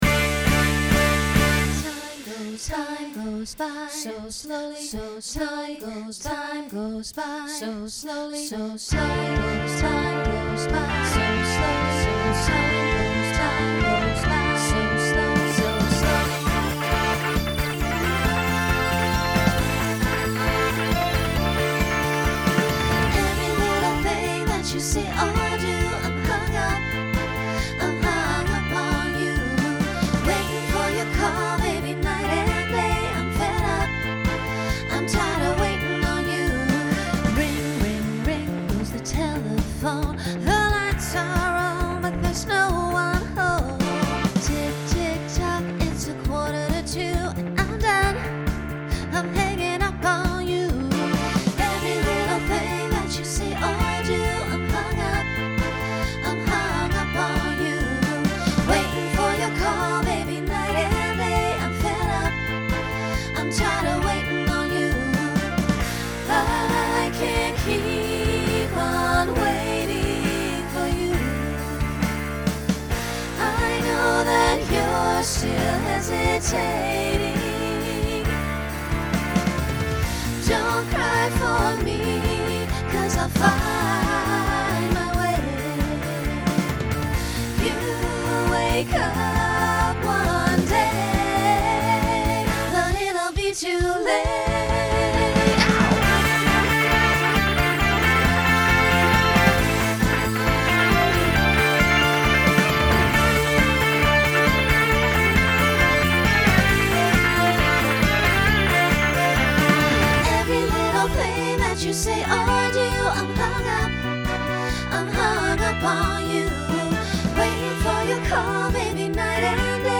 Pop/Dance Instrumental combo
Transition Voicing SSA